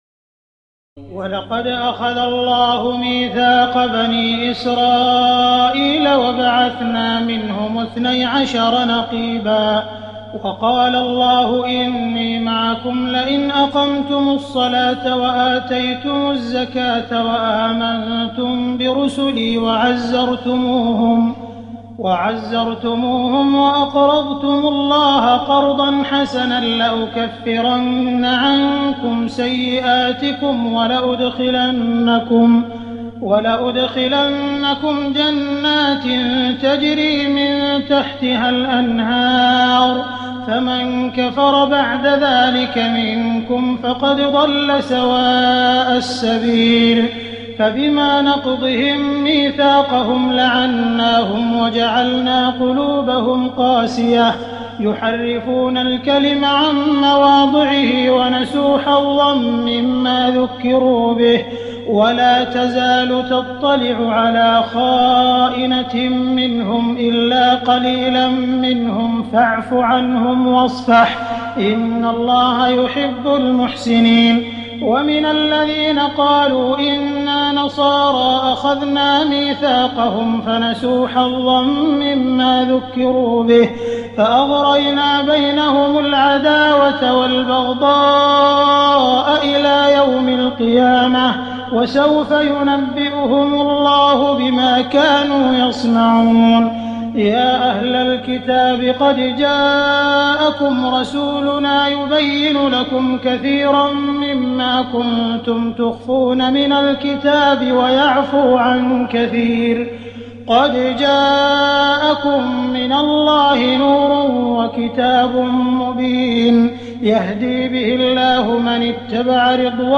تراويح الليلة الخامسة رمضان 1419هـ من سورة المائدة (12-81) Taraweeh 5 st night Ramadan 1419H from Surah AlMa'idah > تراويح الحرم المكي عام 1419 🕋 > التراويح - تلاوات الحرمين